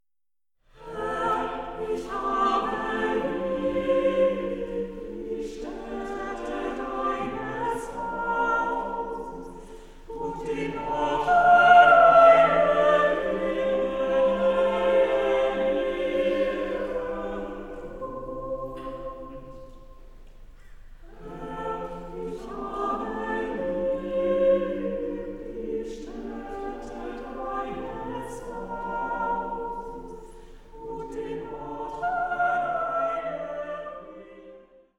Vokalensemble
• kurzweilige Zusammenstellung verschiedener Live-Aufnahmen